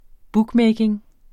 Udtale [ ˈbugˌmεjgiŋ ]